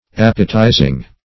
Appetizing \Ap"pe*ti`zing\, adv.